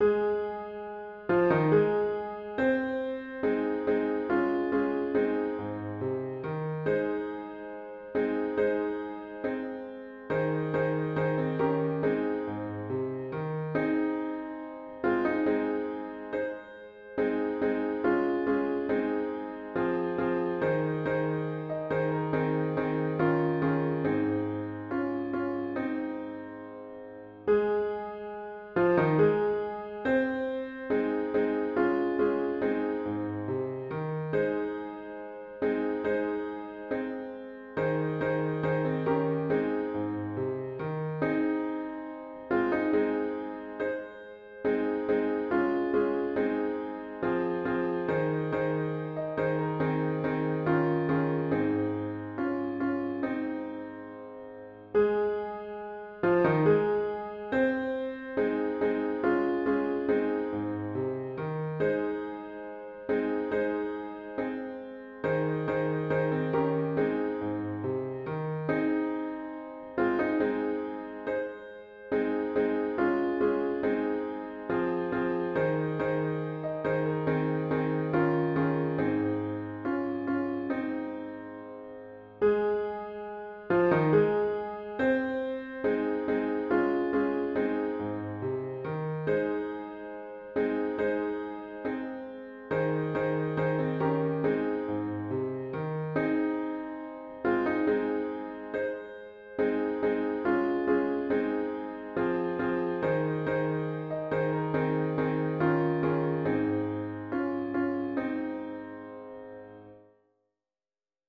HYMN   “Fight the Good Fight”   GtG 846   (verses 1-3)  (Public Domain)